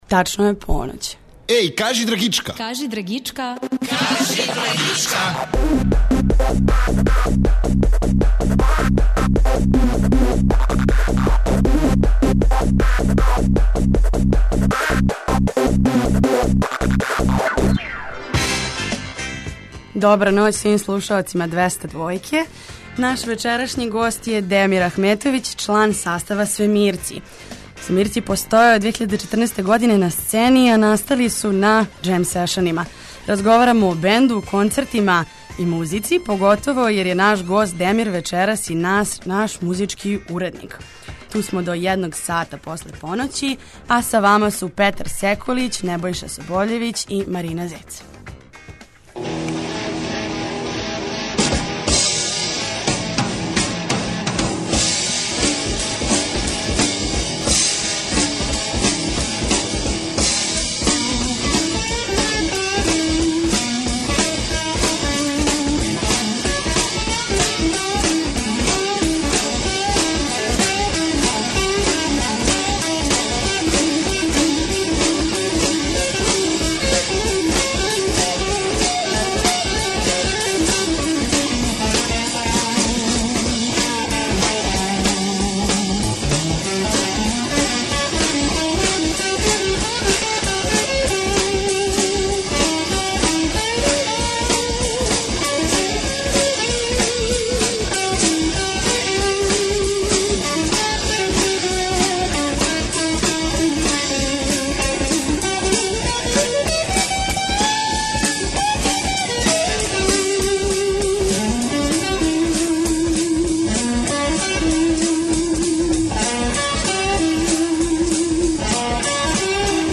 Бенд је установио форму jam session-a за извођење наступа са утицајима фанка, блуза, рока, афро бита и експерименталне музике.